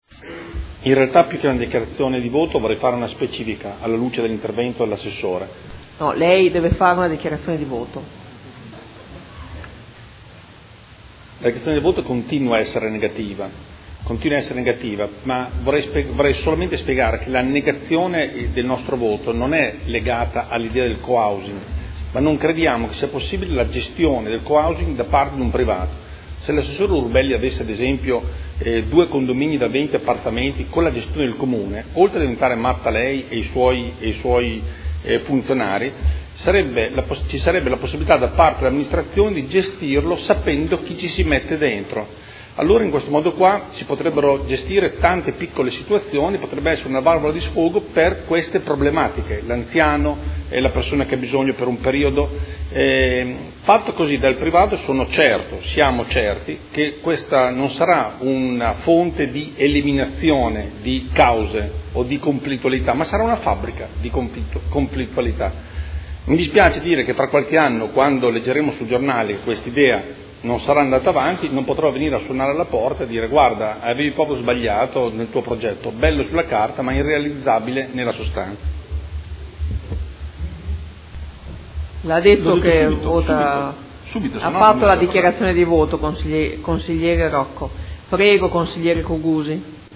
Seduta del 3/12/2015.
Dichiarazione di voto